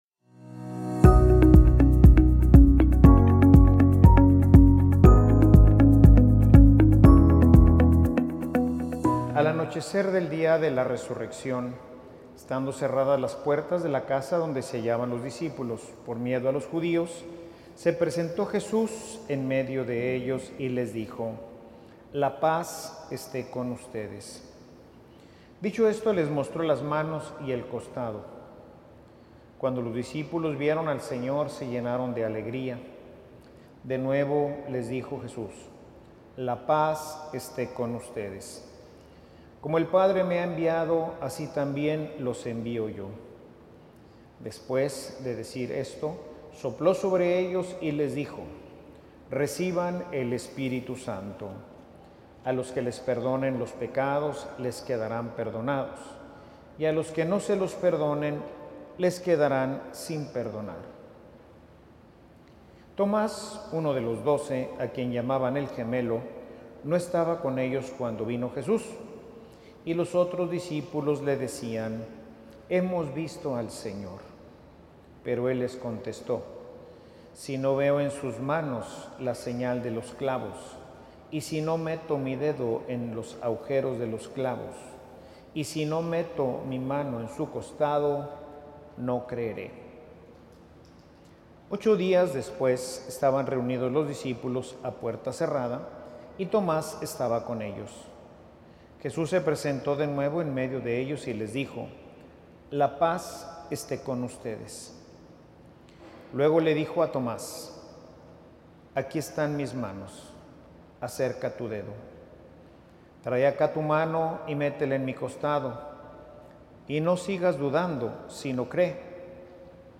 Escucha este audio aquí 0:00 0:00 0.5x 0.75x Normal 1.25x 1.5x Mira el video de esta homilía Ver en YouTube Jesús nos invita a dejar nuestras dudas y creer que Él, verdaderamente ha resucitado.
Homilia_Portadores_de_la_paz.mp3